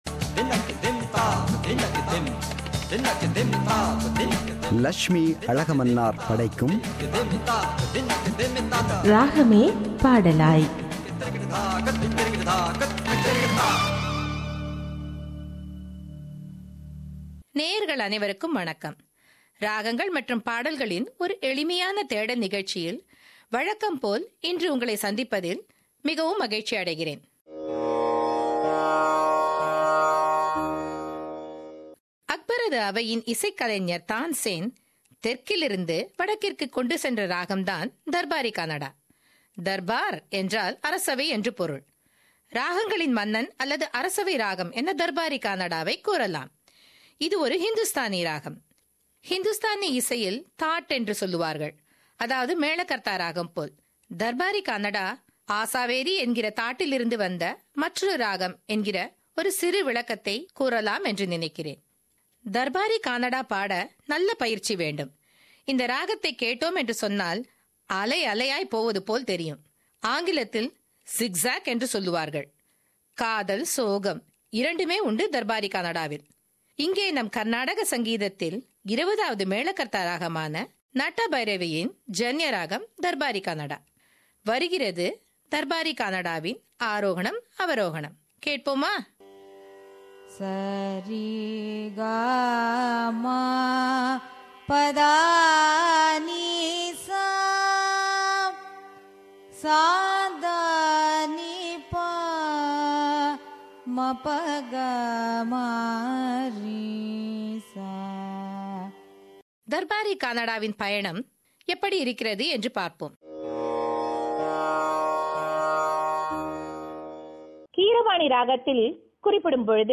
“Ragame Padalaay” – Musical Program –Part 7